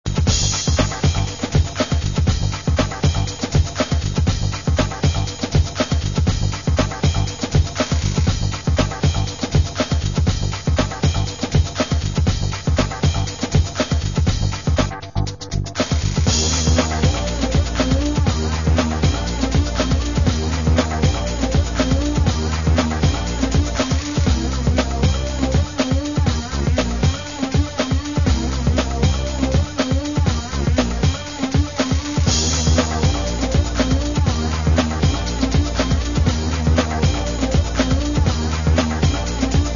Segunda maqueta con tonos electro-rock bailables.